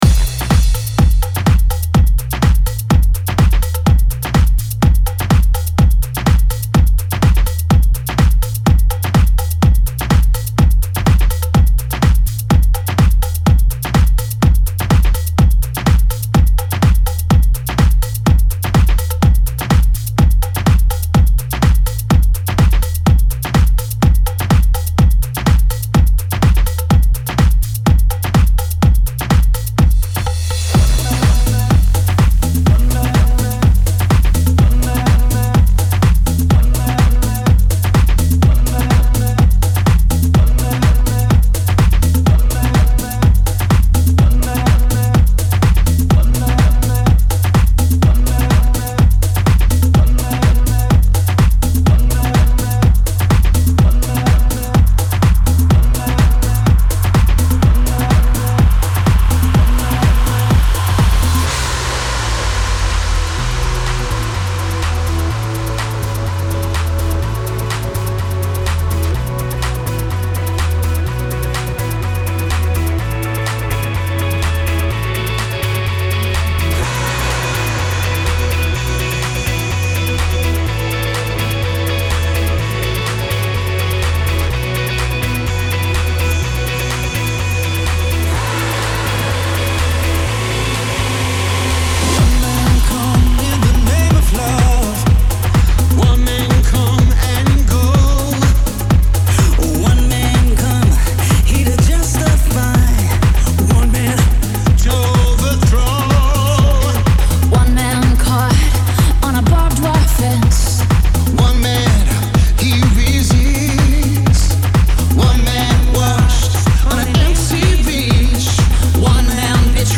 Extended Club